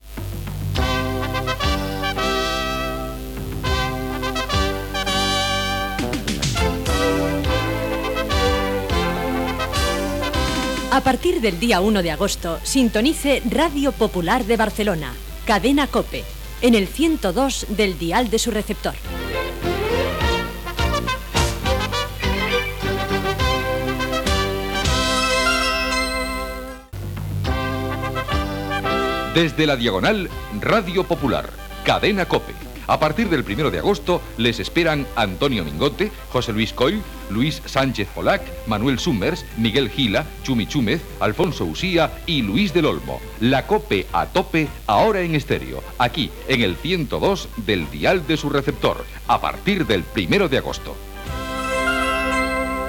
Emissió en període de proves. Avís de l'inici de la programació el dia 1 d'agost
FM